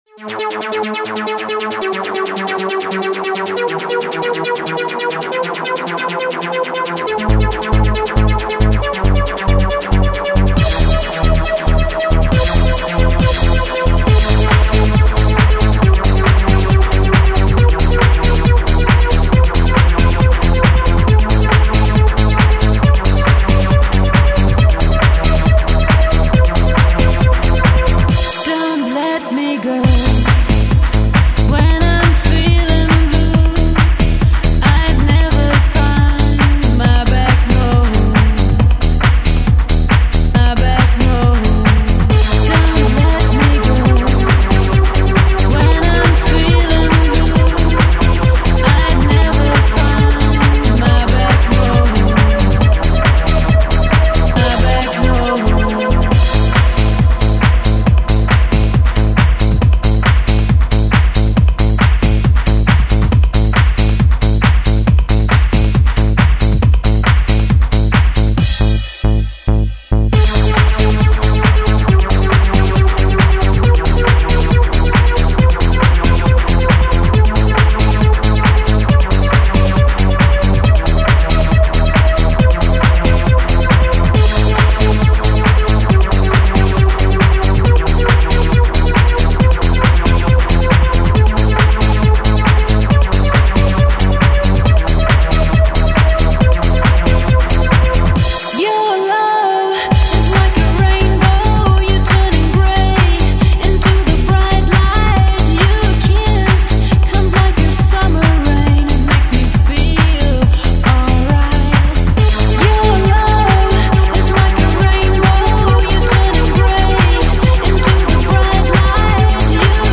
Не законченные миксы из моего архива